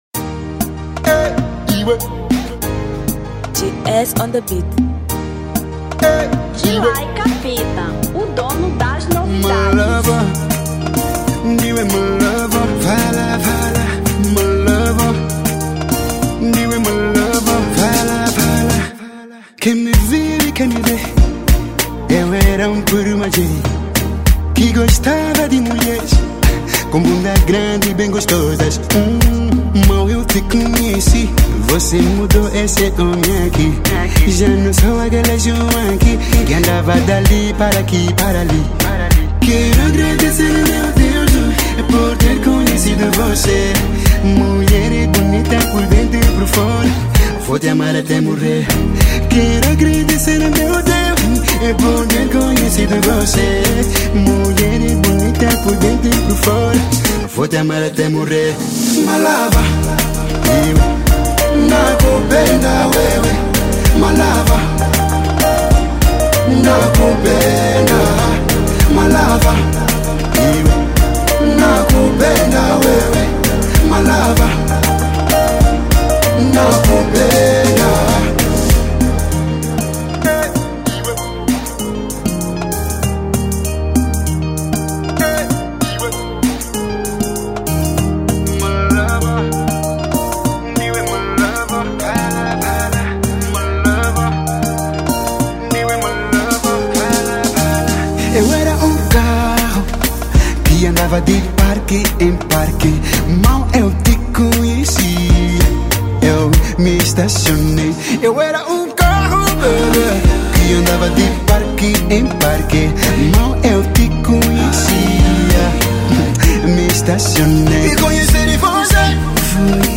Zouk 2018